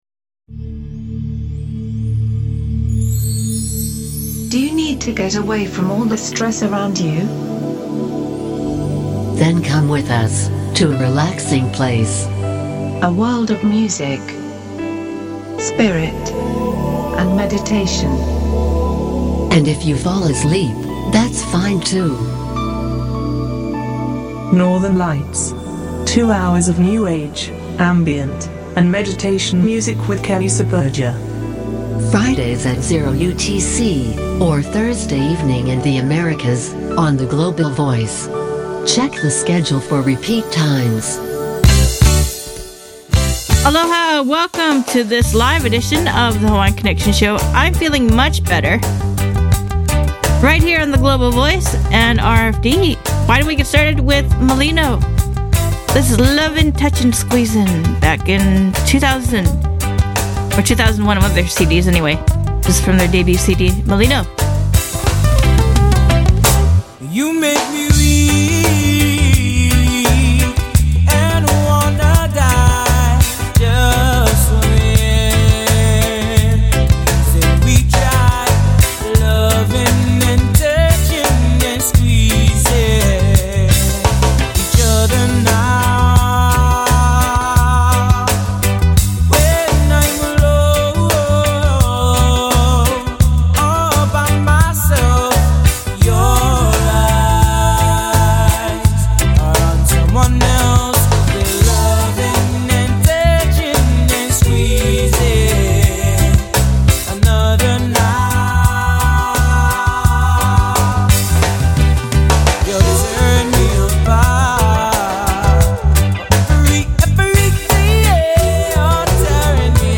Playing the best in contemporary Hawaiian Music, praise and worship, with a little bit of soul in between from these islands.